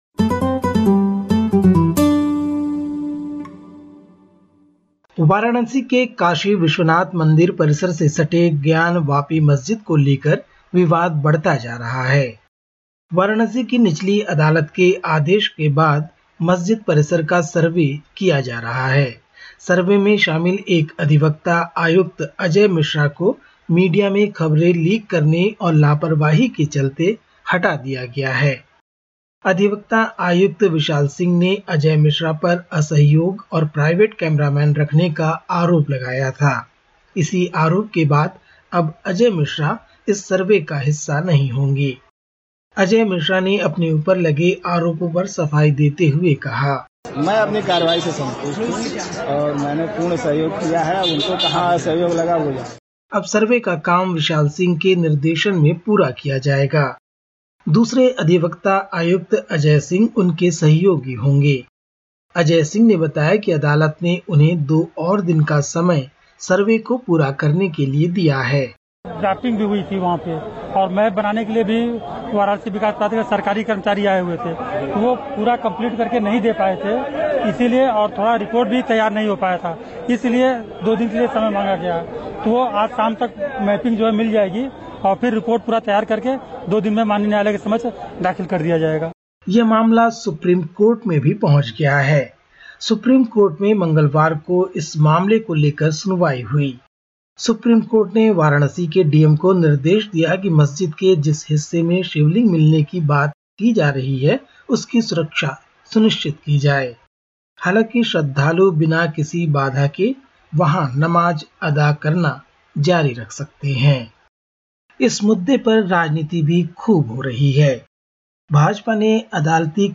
Listen to the latest SBS Hindi report from India. 18/05/2022